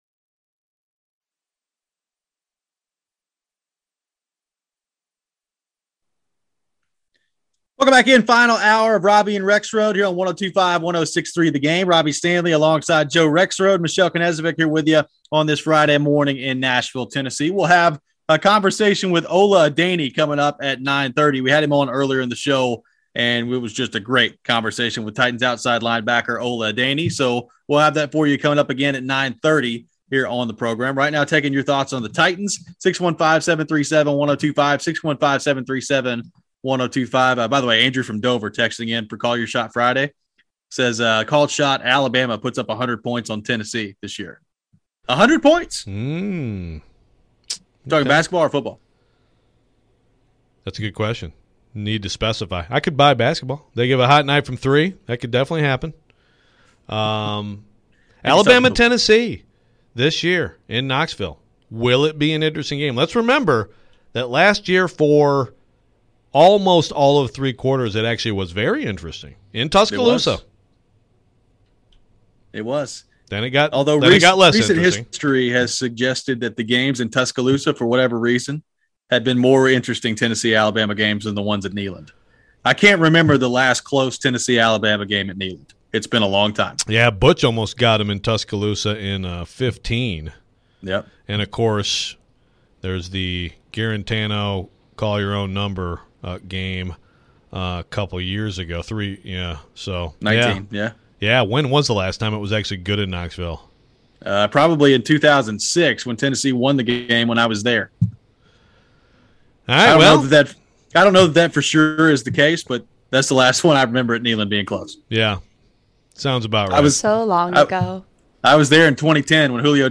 live from the car heading to the Titans training camp